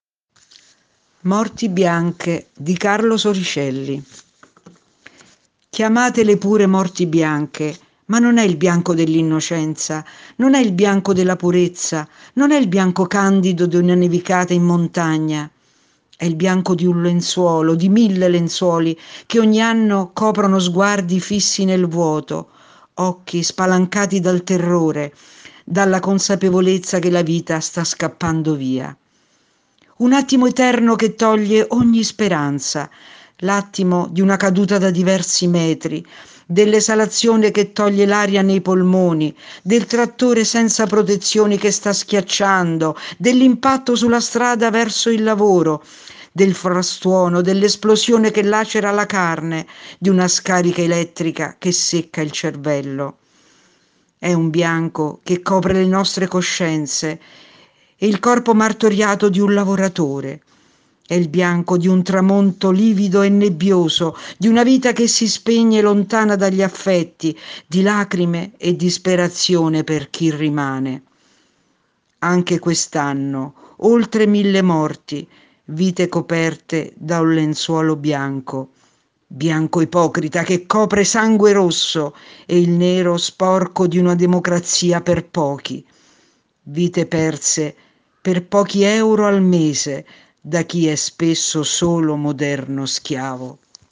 / Lettura ad Alta Voce